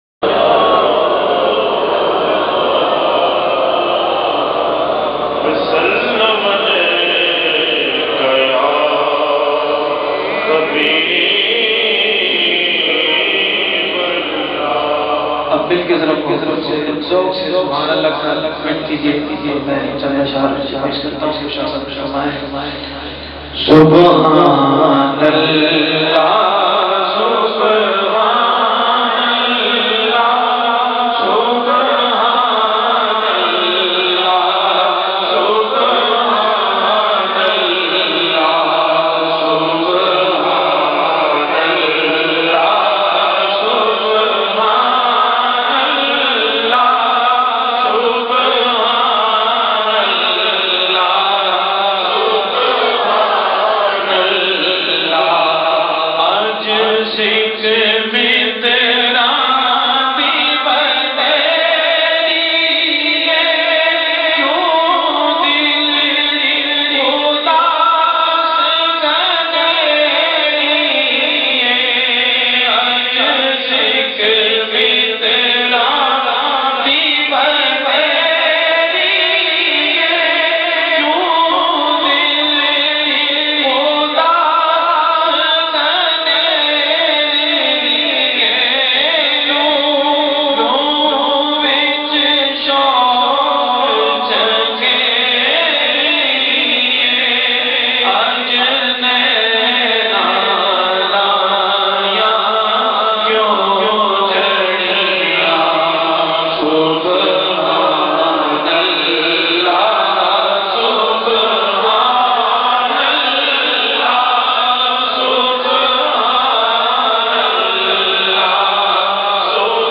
very sweet and magical voice with wonderful control